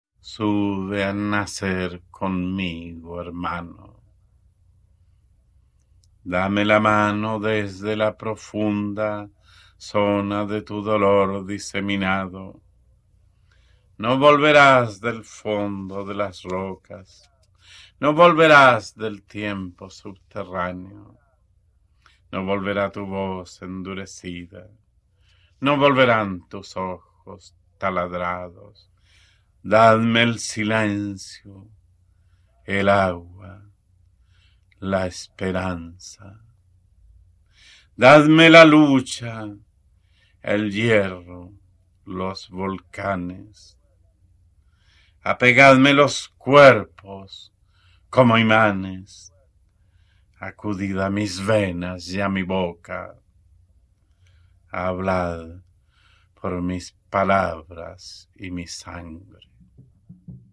Читает Пабло Неруда